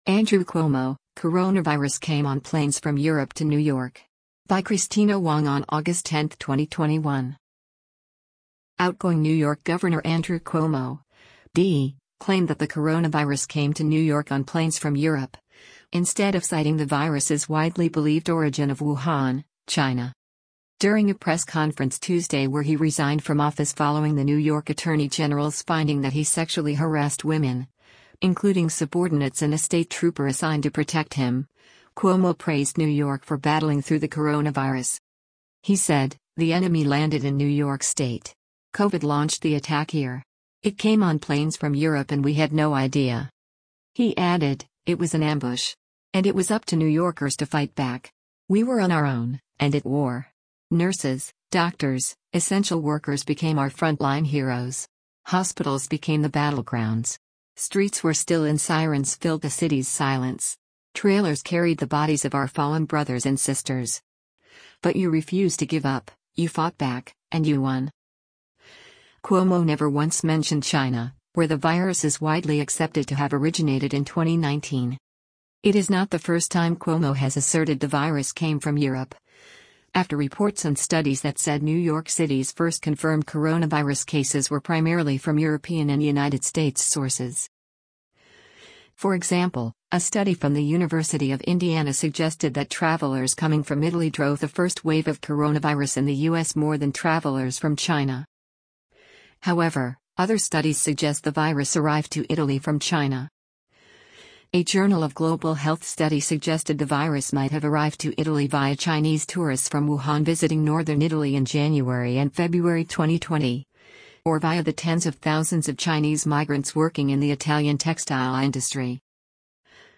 During a press conference Tuesday where he resigned from office following the New York attorney general’s finding that he sexually harassed women, including subordinates and a state trooper assigned to protect him, Cuomo praised New York for battling through the coronavirus.